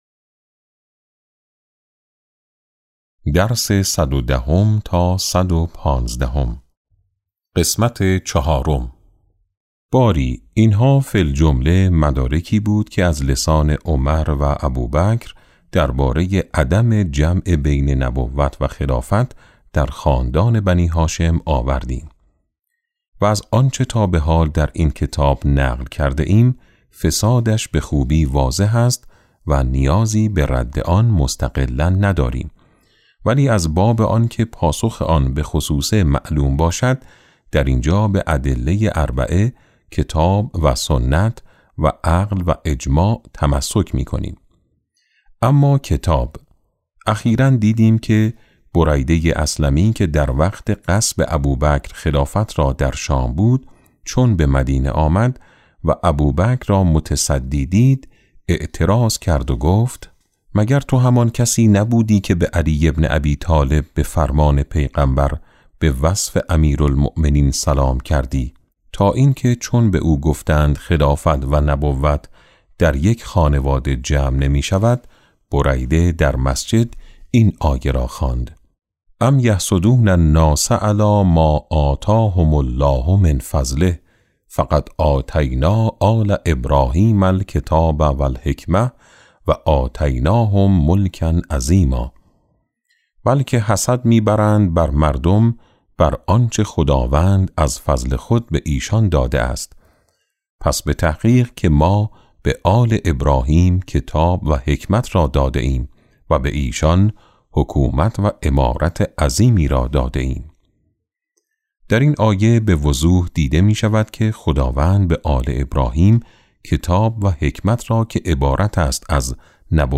کتاب صوتی امام شناسی ج۸ - جلسه7